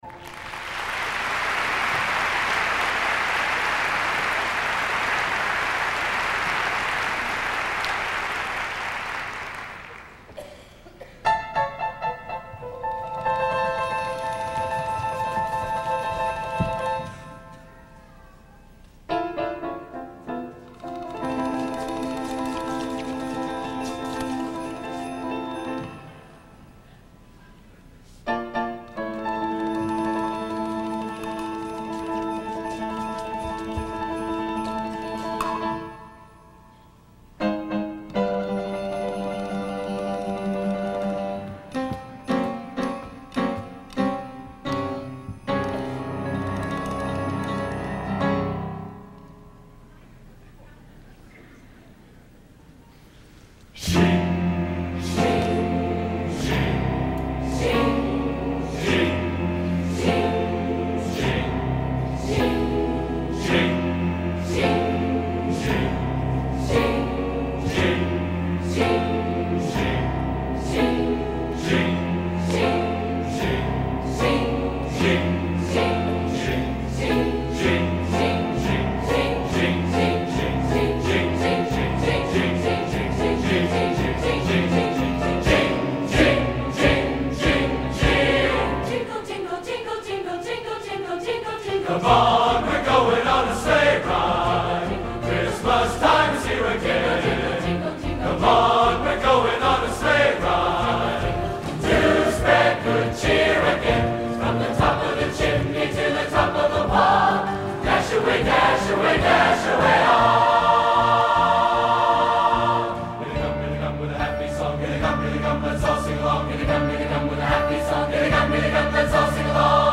Location: West Lafayette, Indiana
Genre: | Type: Christmas Show |